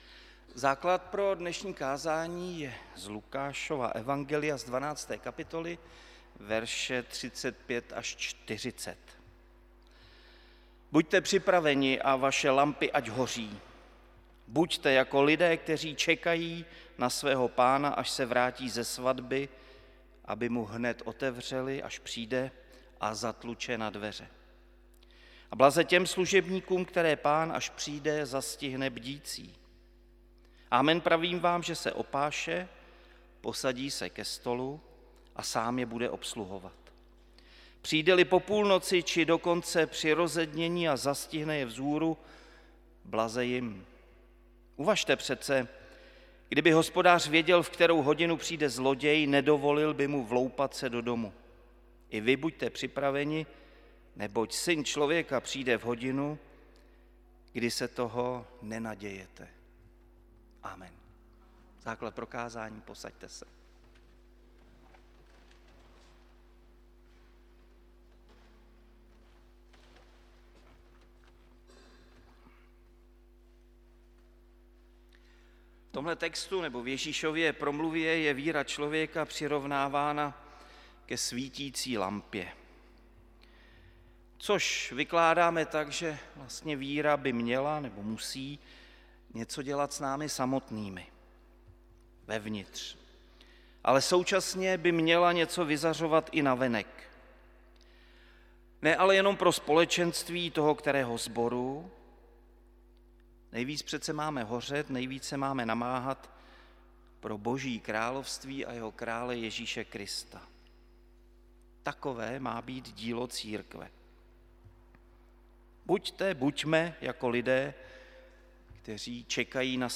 XIV. neděle po sv. Trojici 1. září 2024 – bohoslužby se slavením sv. Večeře Páně